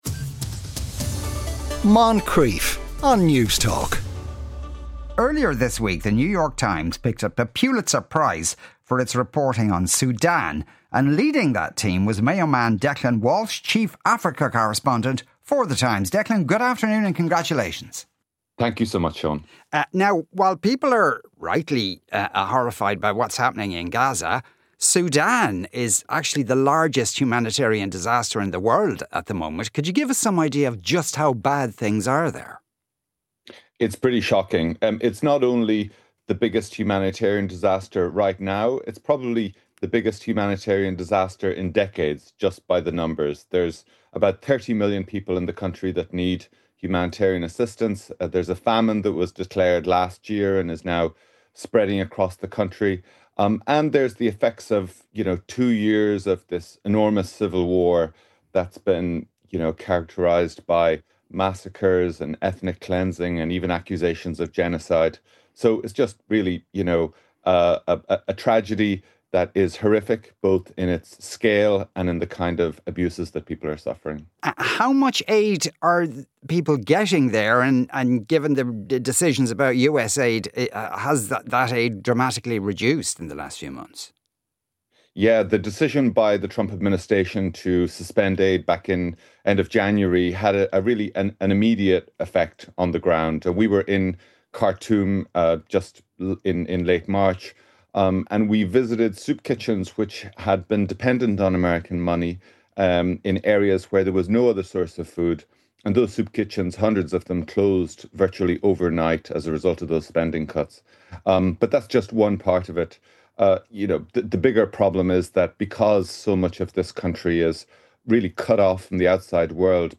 Pulitzer Prize Winning Journalist and Chief Africa Correspondent for the New York Times, Declan Walsh joins Seán to discuss.